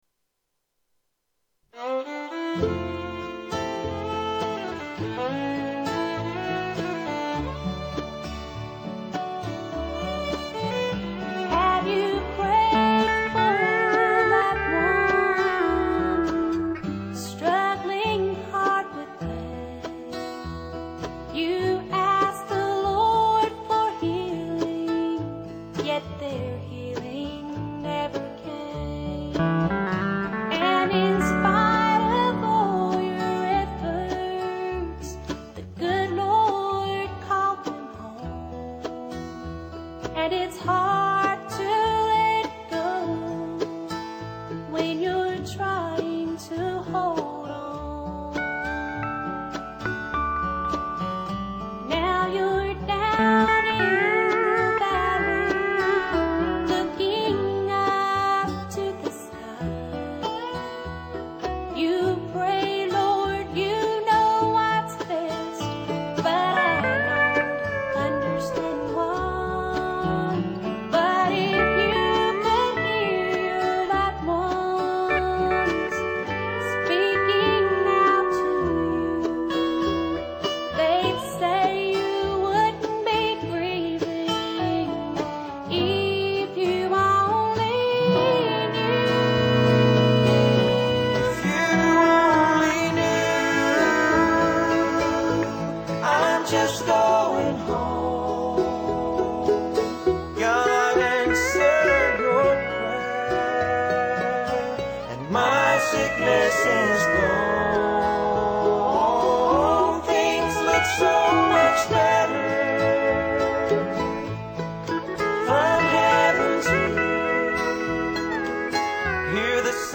I recently remixed all the songs in stereo.